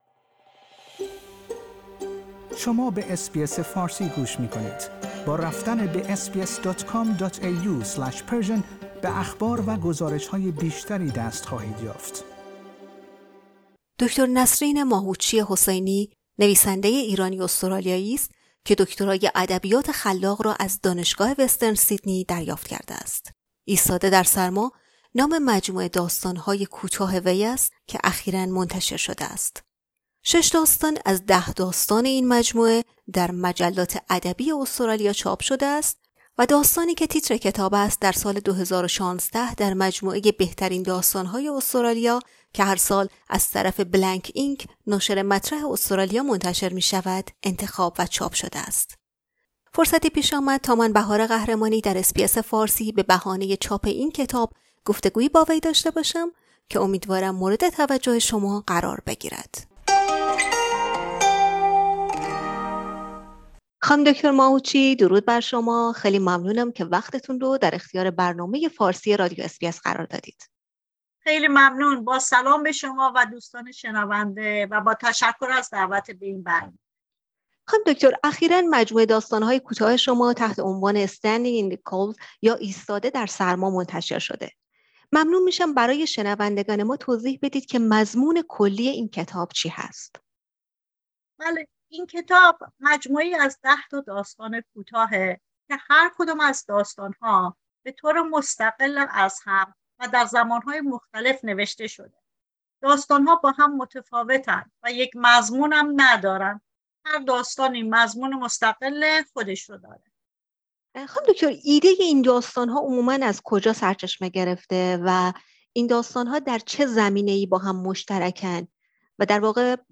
"ایستاده در سرما" - گفتگو